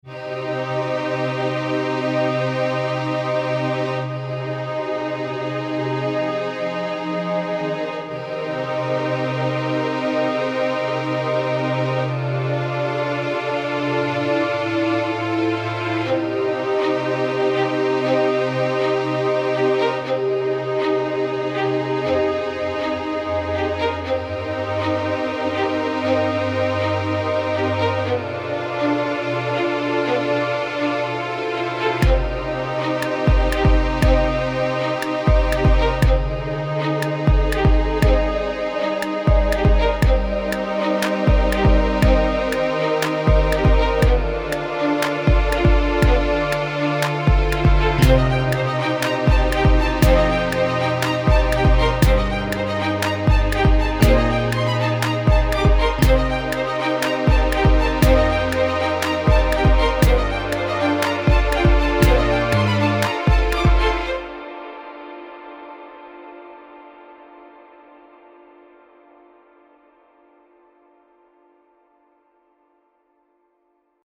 It builds up... but where does it go?